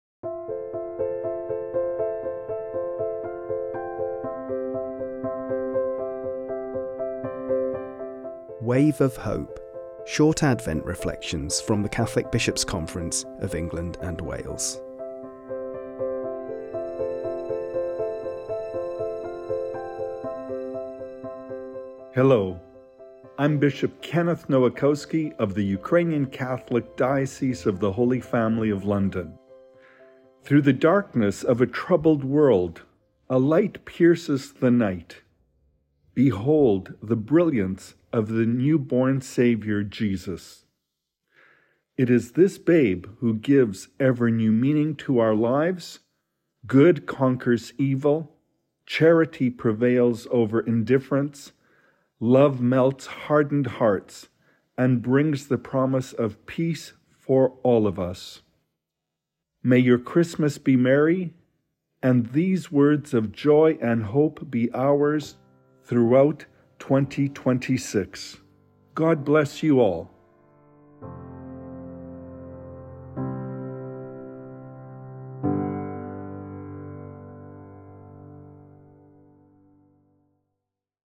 This 'Wave of Hope' Advent reflection is given by Bishop Kenneth Nowakowski, Eparchial Bishop of the Ukrainian Greek Catholic Eparchy of the Holy Family of London.